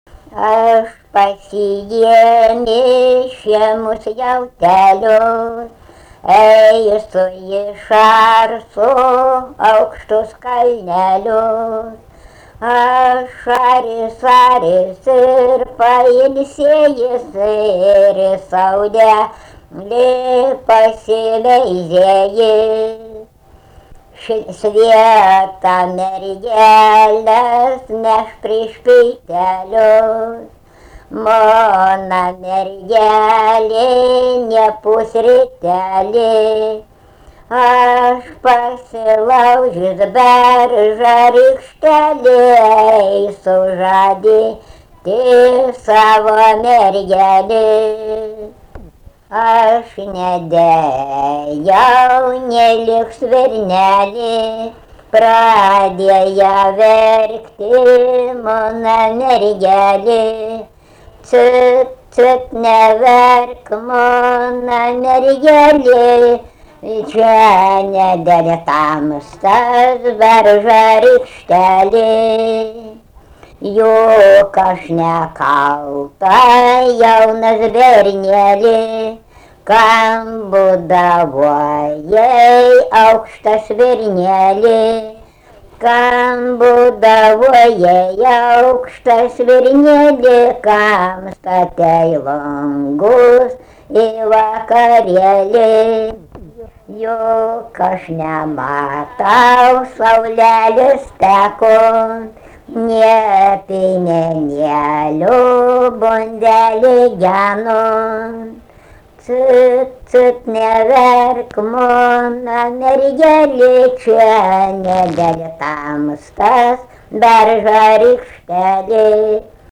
daina, žaidimai ir rateliai
Erdvinė aprėptis Barvydžiai
Atlikimo pubūdis vokalinis